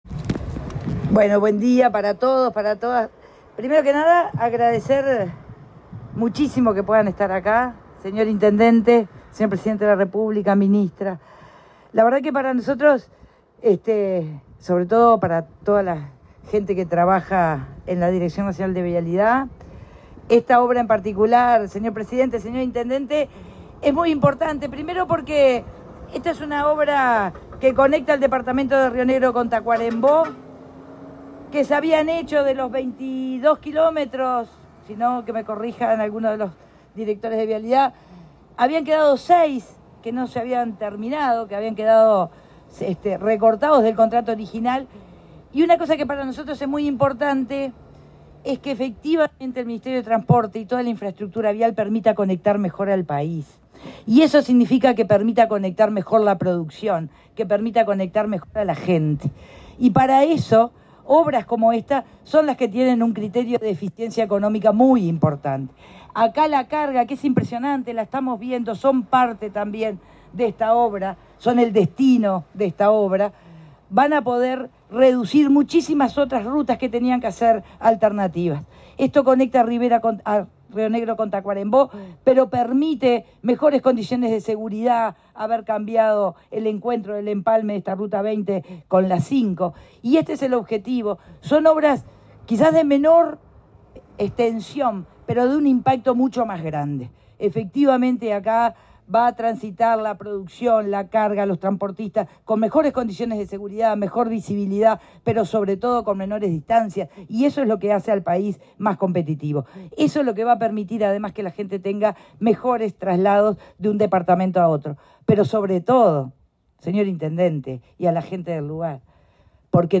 Palabras de la ministra de Transporte, Lucía Etcheverry
En la inauguración de obras de infraestructura vial en ruta n°20, que completa la conectividad con ruta n°5, en el departamento de Tacuarembó, se